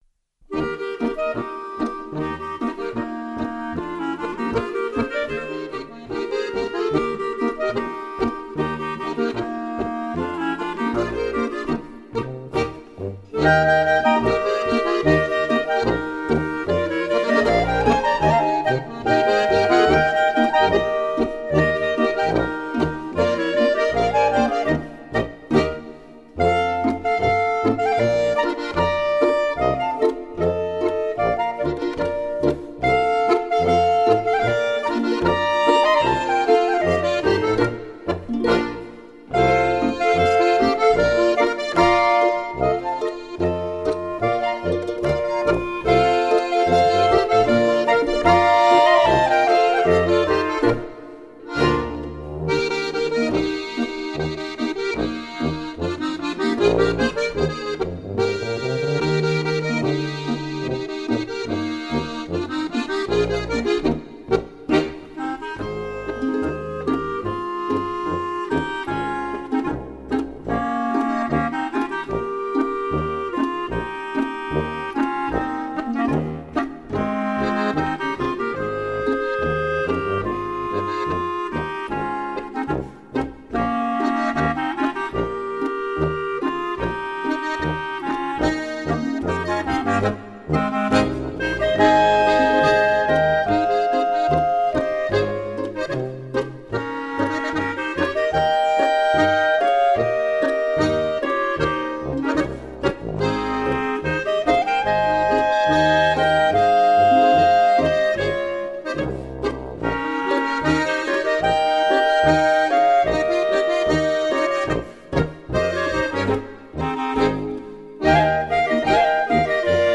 Gattung: Volksmusikstücke
Besetzung: Volksmusik/Volkstümlich Weisenbläser